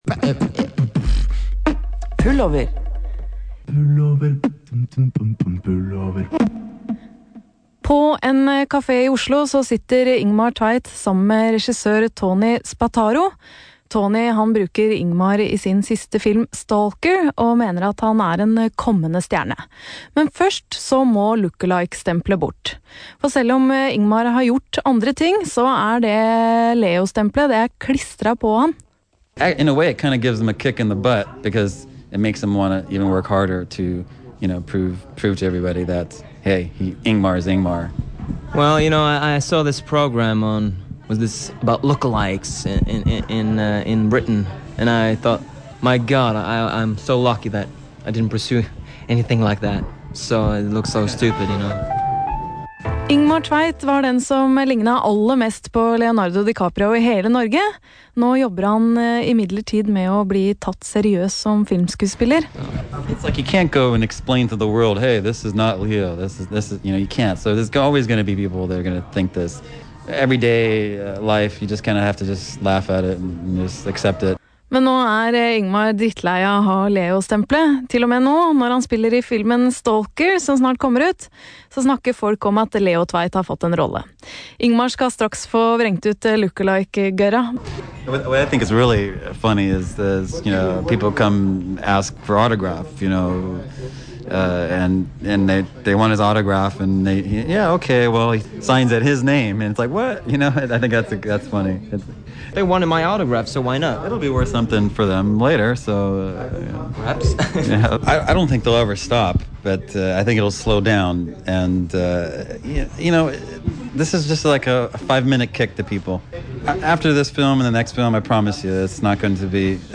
chat live on PETRE Radio, a national broadcaster in Norway.